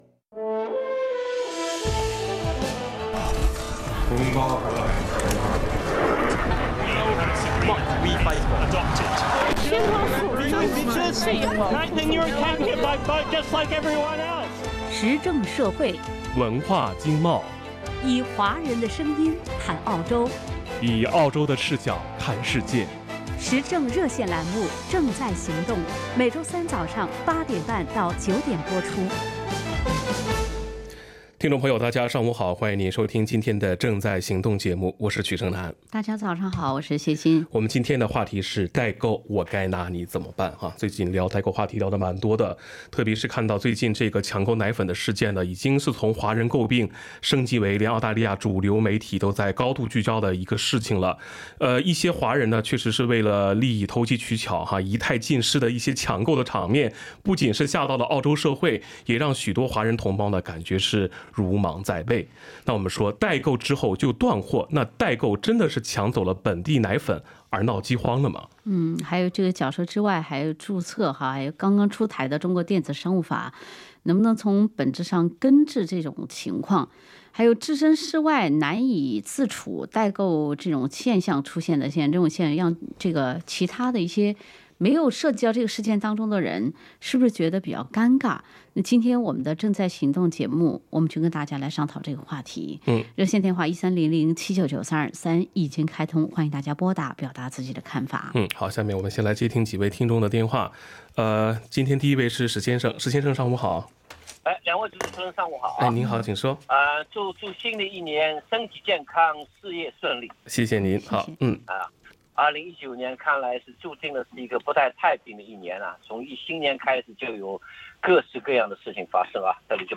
action_talkback_jan_16.mp3